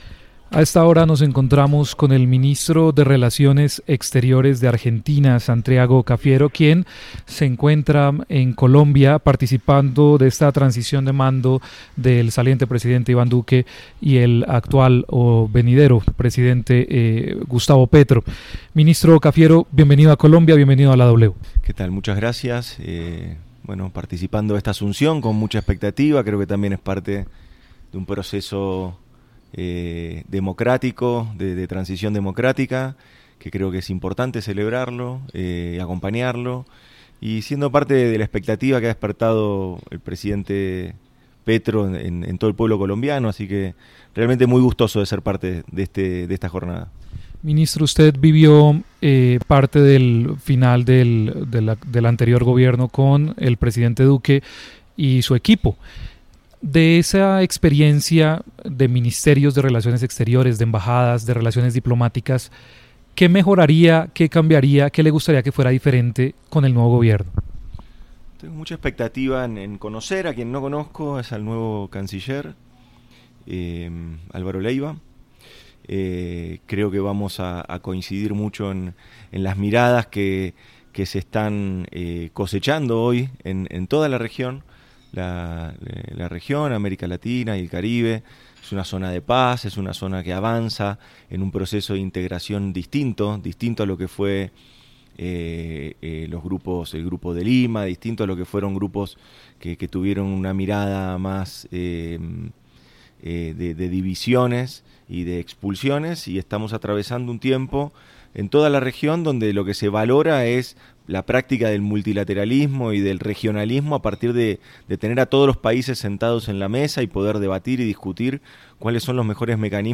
En entrevista con W Radio, Cafiero comentó que tiene “mucha expectativa” por conocer al nuevo canciller colombiano Álvaro Leyva y cree que coincidirán mucho en las “miradas que se están cosechando hoy en toda la región, como una zona de paz que avanza en un proceso de integración distinto a lo que es el Grupo de Lima o grupos que tuvieron en el pasado una mirada de divisiones y de expulsiones”, dijo.